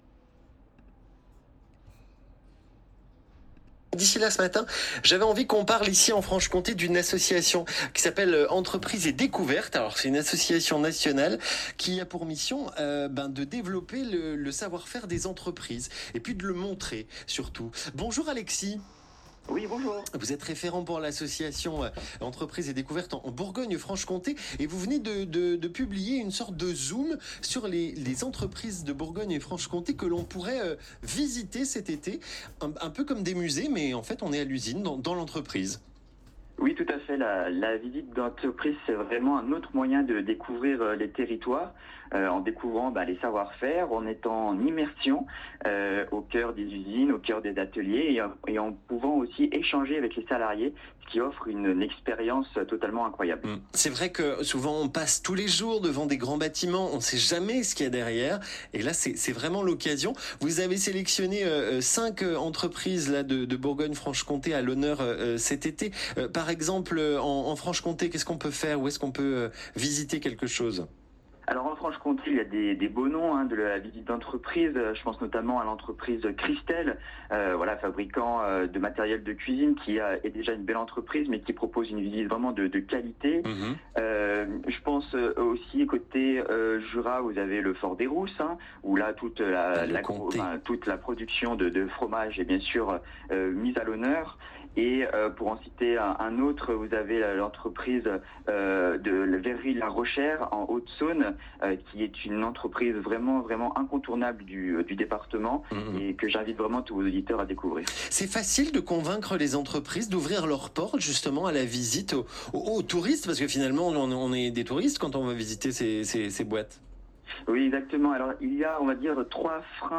🎙 Interviews radio